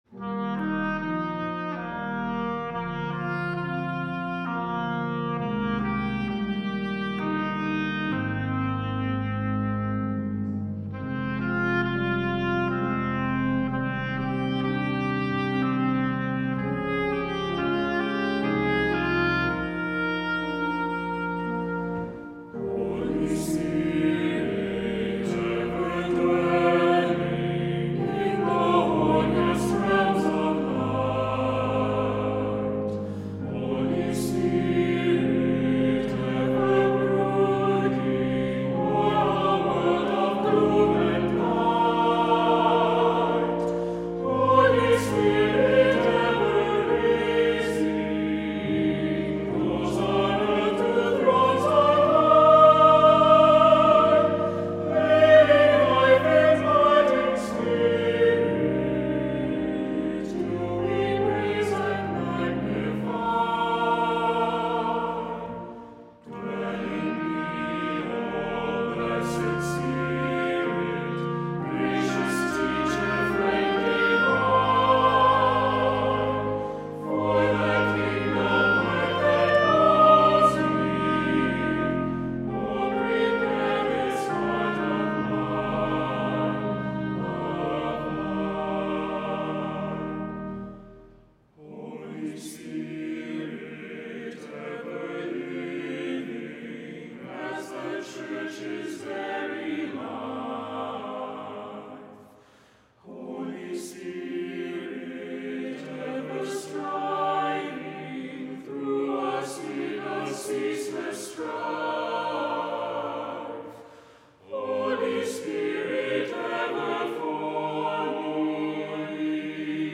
Voicing: SATB; SSAA; Assembly